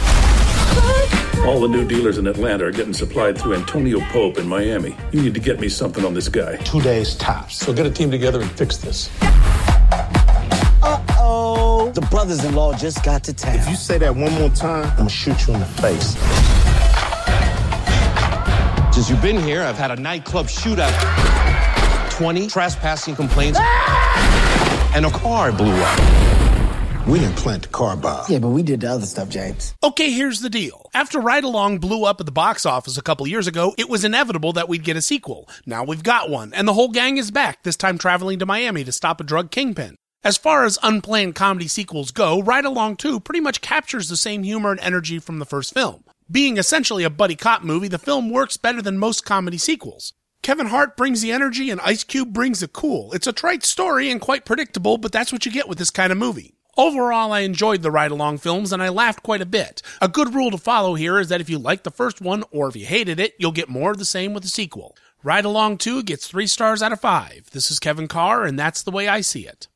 radio review…